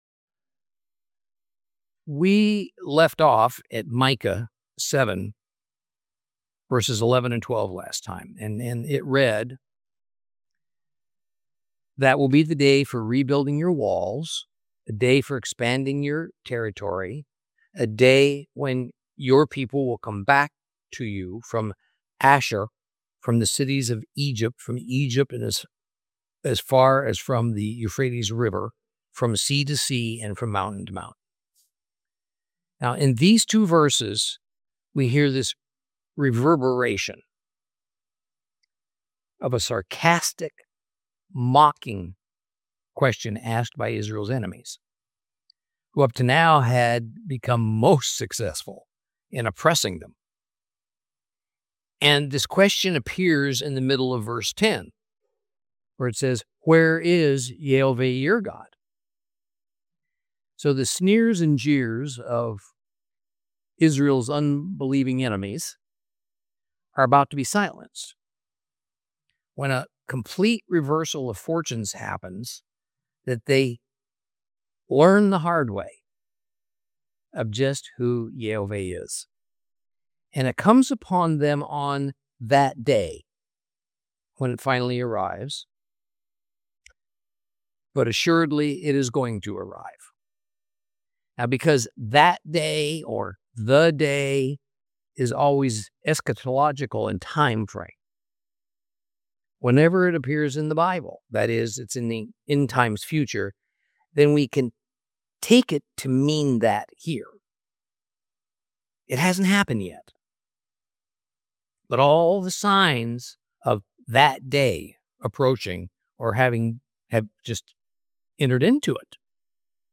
Teaching from the book of Micah, Lesson 15 Chapter 7 concluded END.